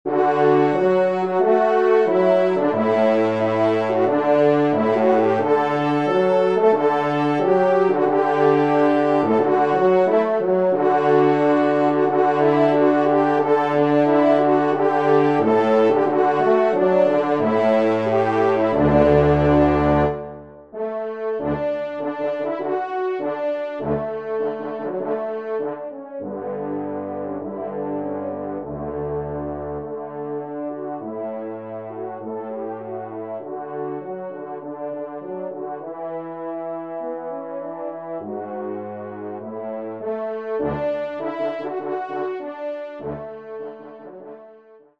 Pupitre Basse (en exergue)